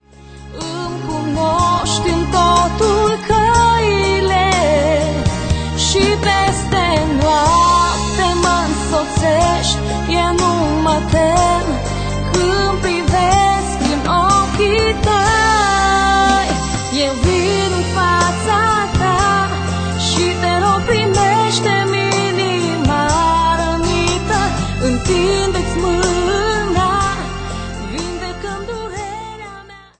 Personalitate, forta, dinamism, energie si originalitate.